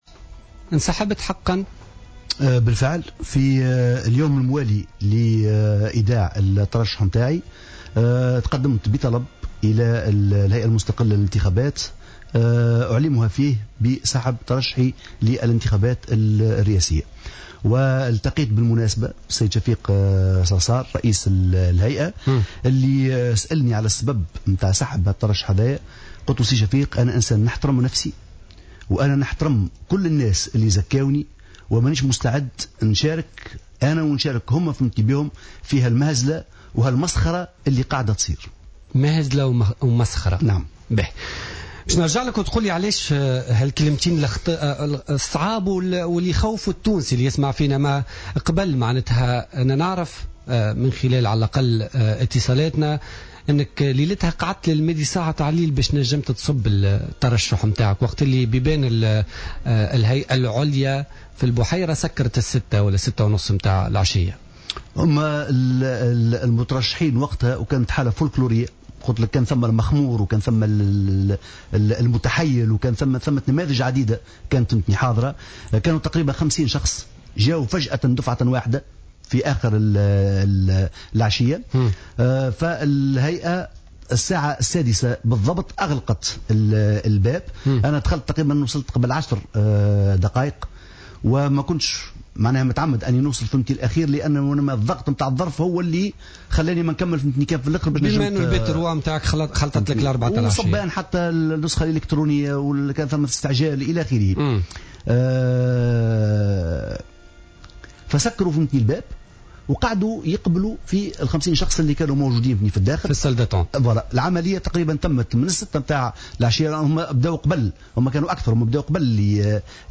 أكّد الإعلامي زياد الهاني اليوم الخميس في مداخلة له في برنامج "بوليتيكا" سحب ترشّحه من الانتخابات الرئاسية.